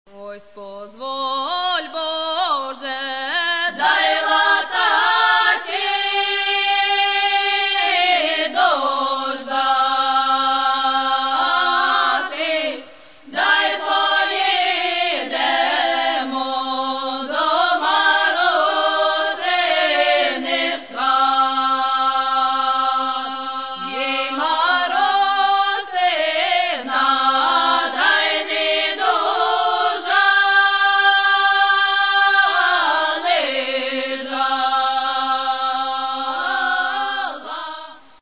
Каталог -> Народная -> Акапельное пение и хоры